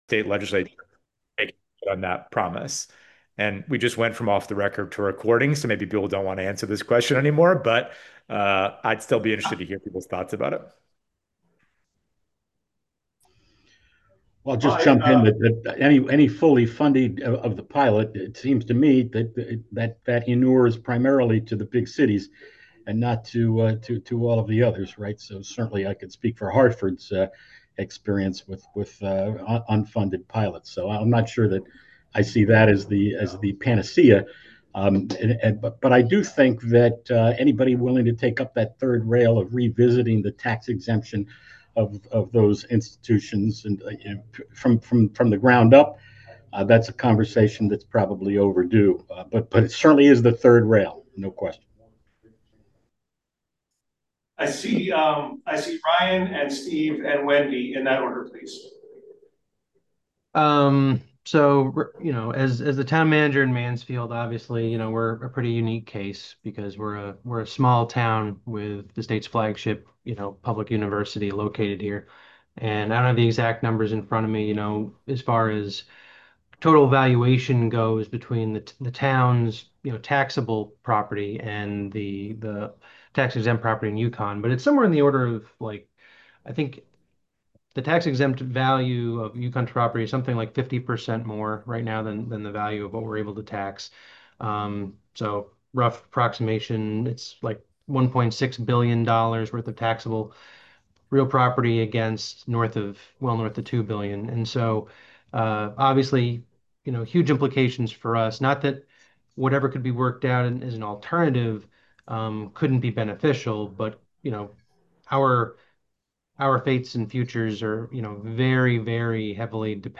SPECIAL CRCOG POLICY BOARD This meeting is hybrid.
Comptroller Sean Scanlon to discuss the current state of the property tax system.